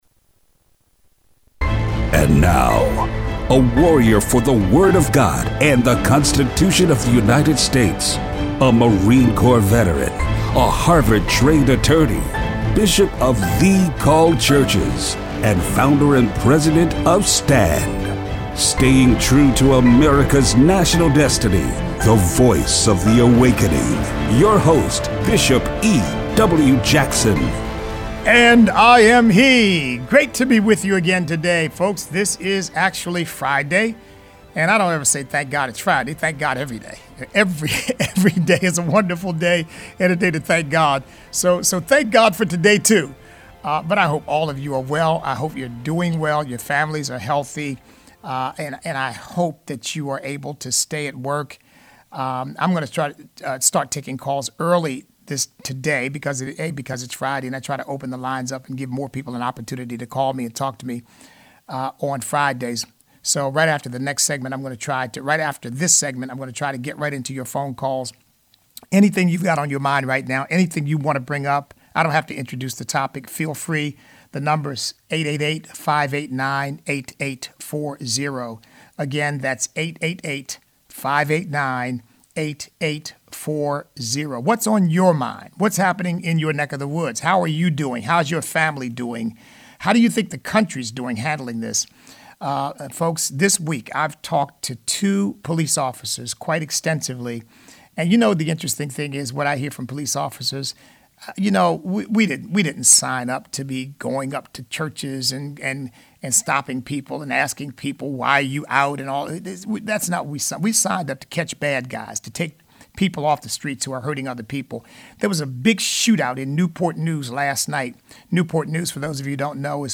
Show Notes Listener call-in